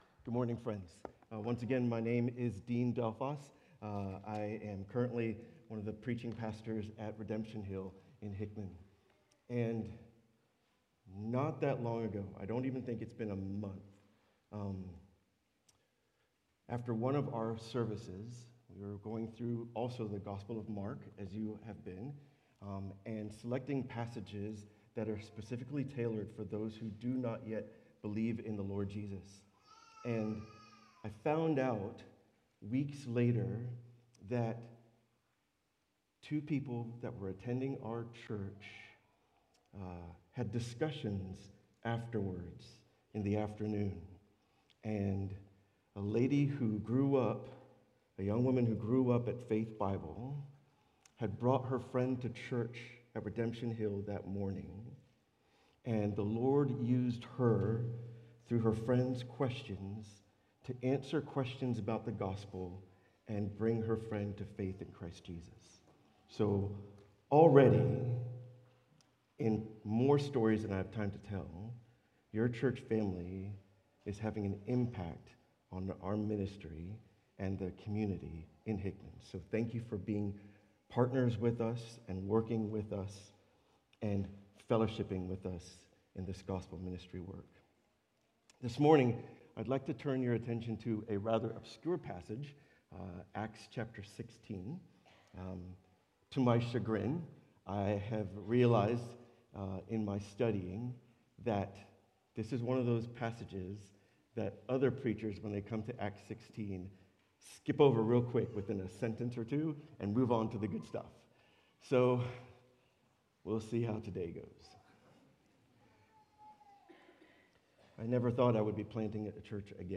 Sermons from Faith Bible Church in Lincoln, NE